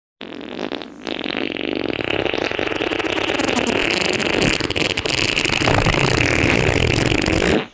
poisonous fart sound effects
poisonous-fart